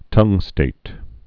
(tŭngstāt)